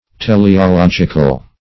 teleological - definition of teleological - synonyms, pronunciation, spelling from Free Dictionary
Teleological \Te`le*o*log"ic*al\, a. [Cf. F.